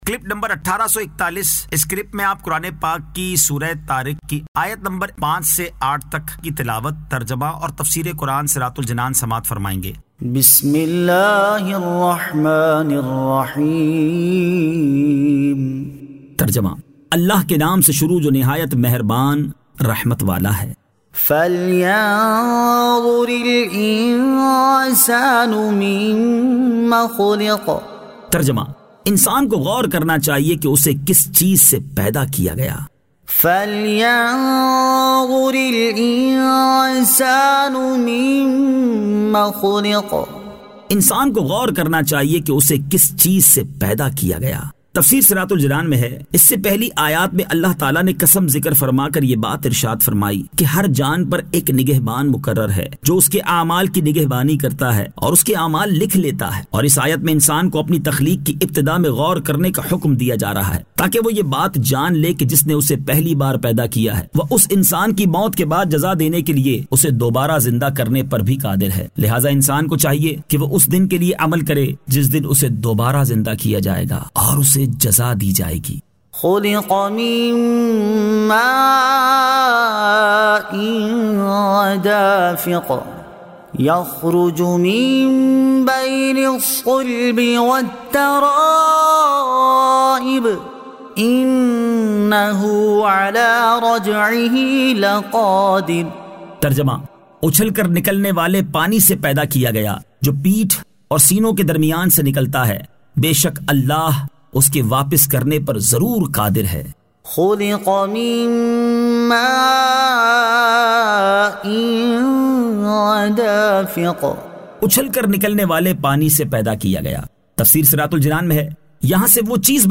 Surah At-Tariq 05 To 08 Tilawat , Tarjama , Tafseer